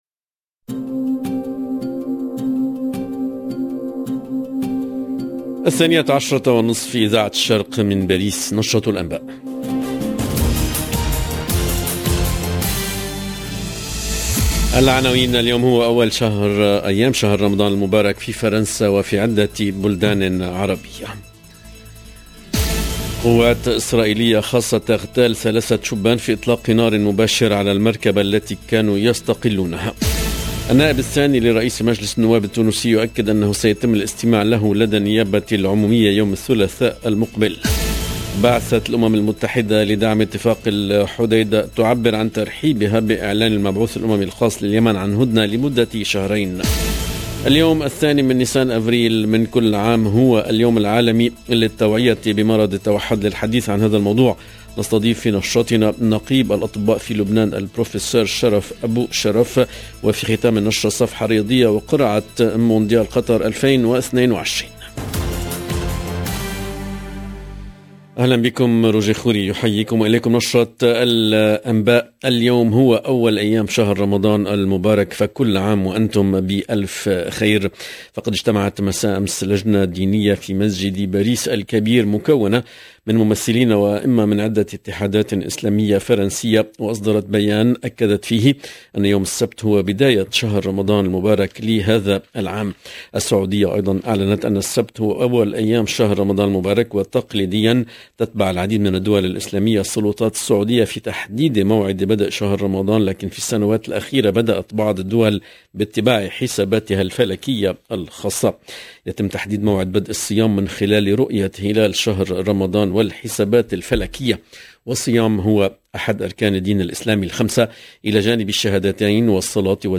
LE JOURNAL EN LANGUE ARABE DE MIDI 30 DU 2/04/22